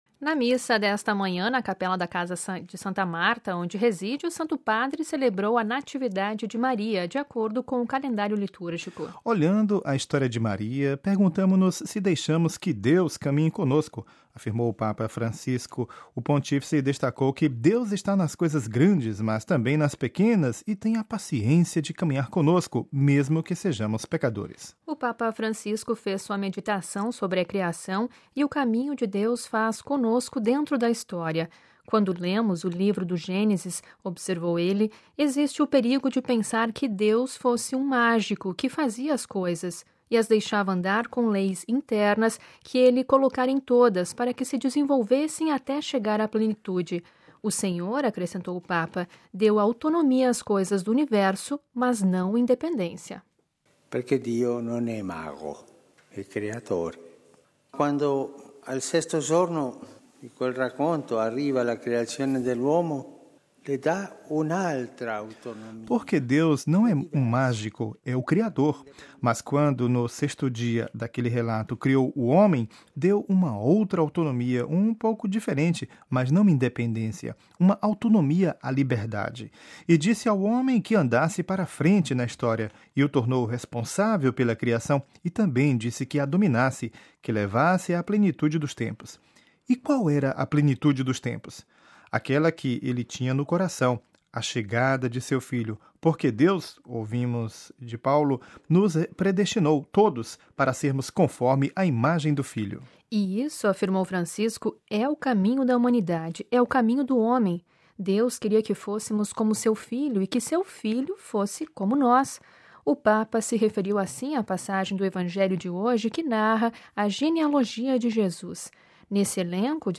MP3 Cidade do Vaticano - (RV) - Na missa nesta manhã, na capela da Casa de Santa Marta, onde mora, o Santo Padre celebrou a Natividade de Maria, de acordo com o Calendário Litúrgico.